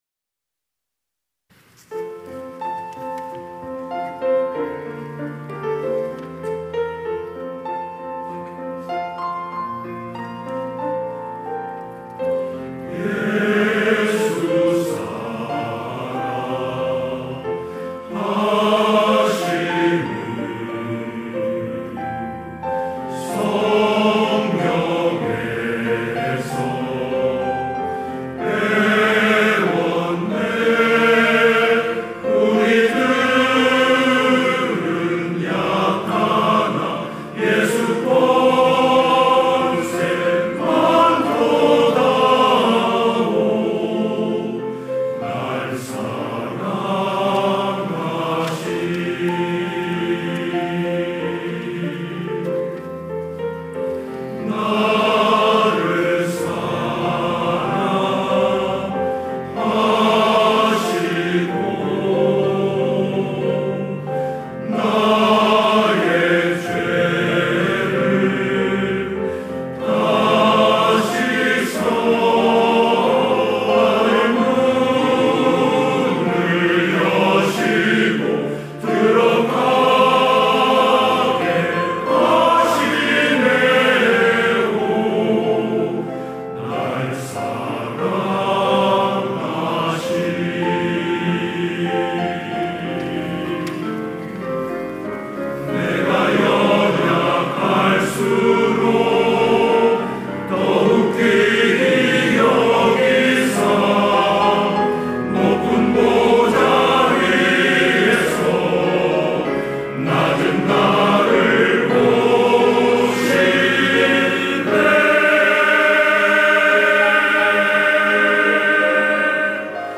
찬양대 남선교회